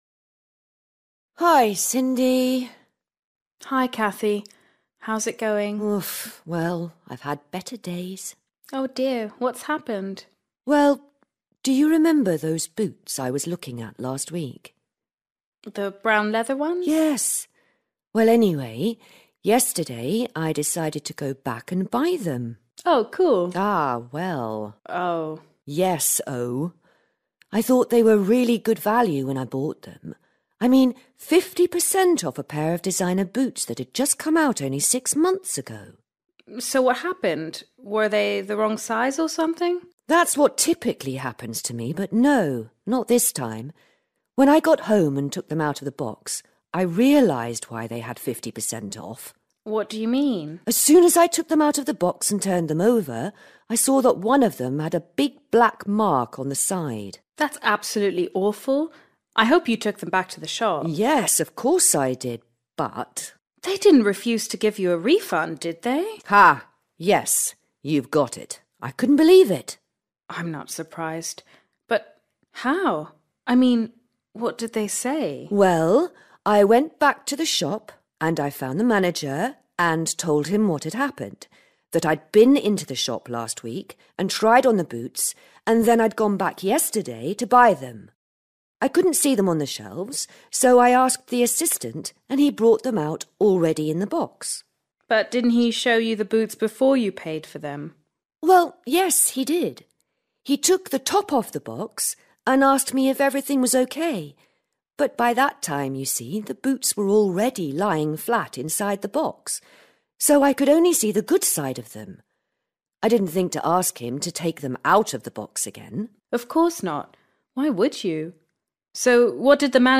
A   Listen to a conversation between two friends